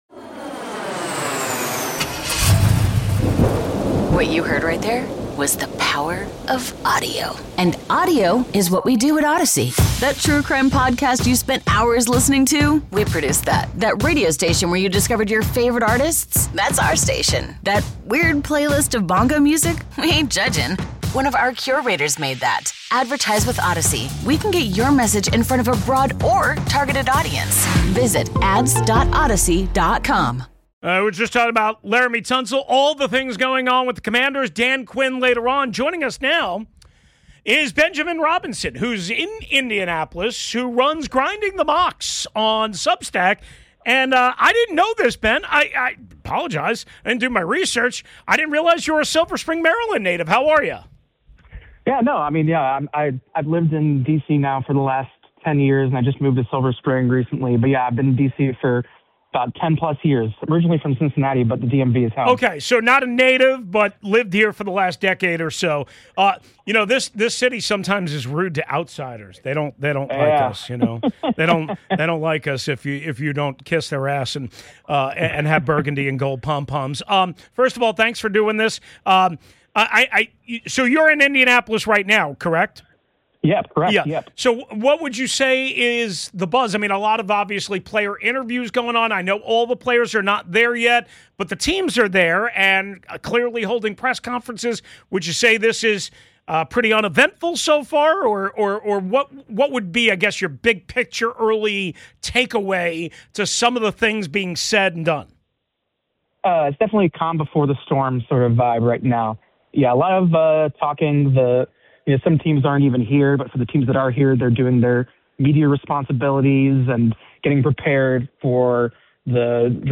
Live From the NFL Combine